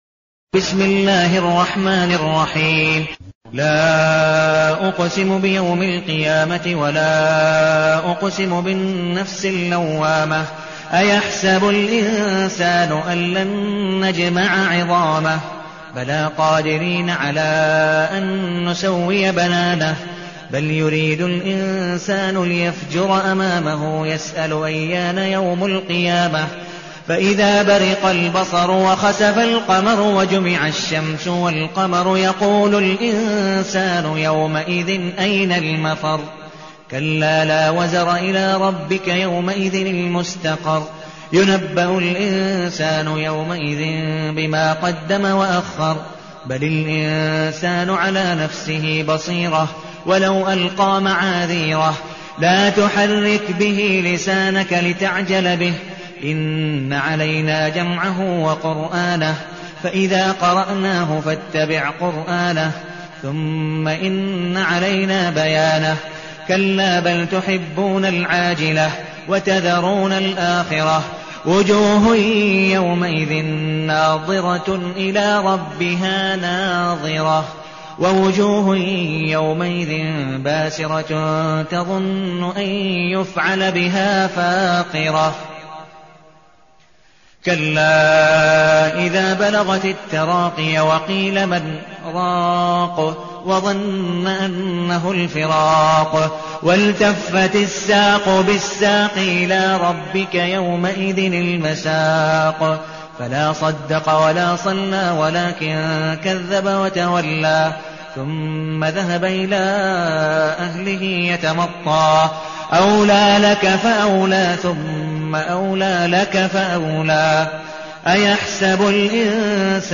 المكان: المسجد النبوي الشيخ: عبدالودود بن مقبول حنيف عبدالودود بن مقبول حنيف القيامة